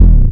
overloadbd.wav